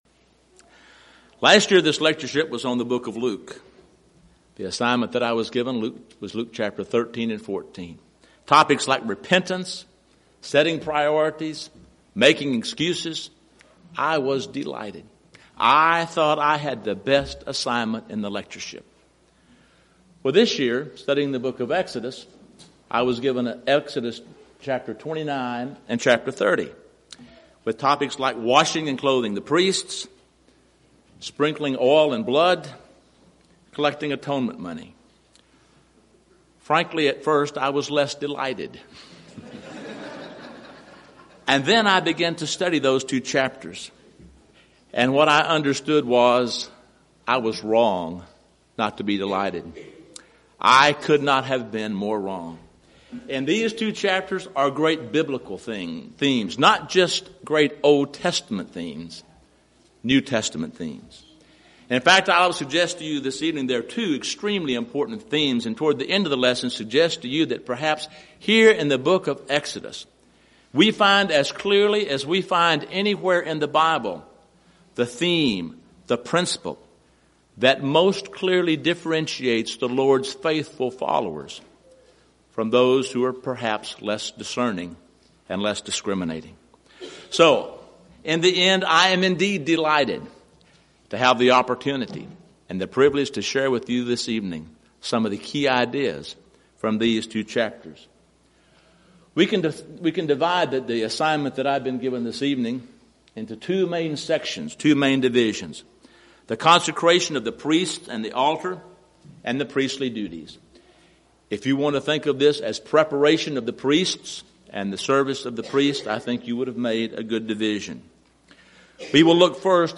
Event: 2nd Annual Schertz Lectures Theme/Title: Studies In Exodus